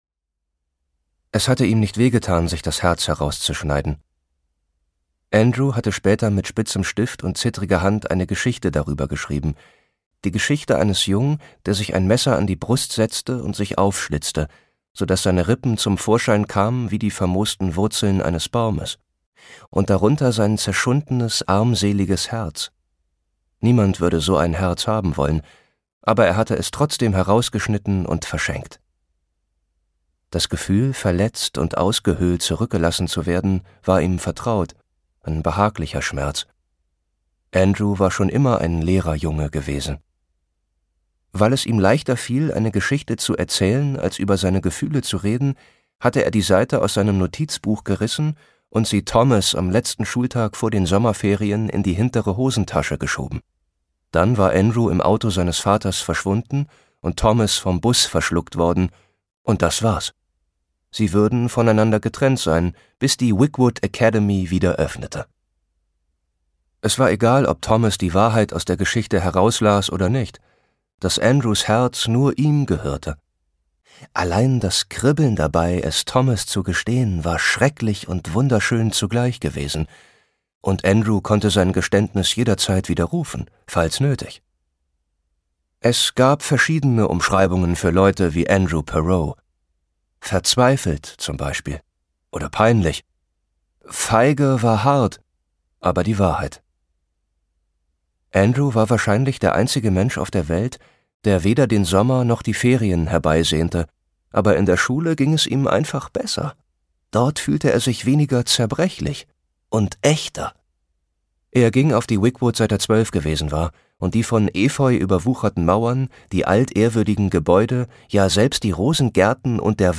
Dabei lotet er die psychologischen Tiefen gekonnt und einfühlsam aus.